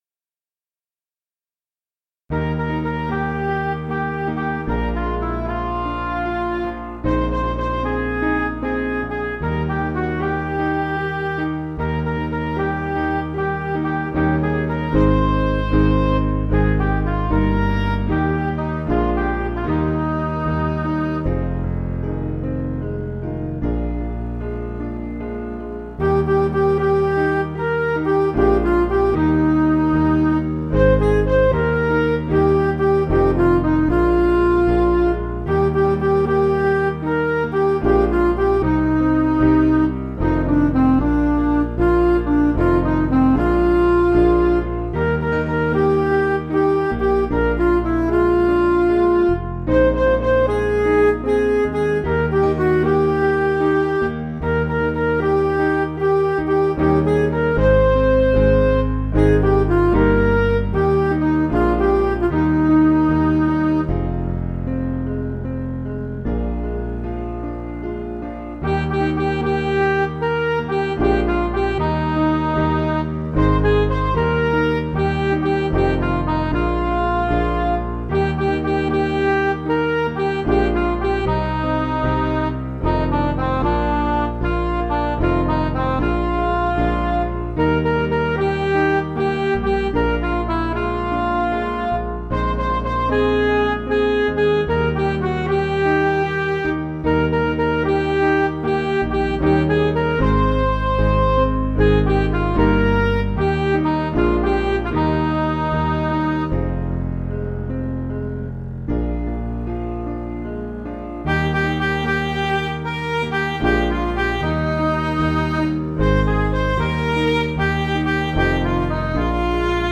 Piano & Instrumental
(CM)   3/Eb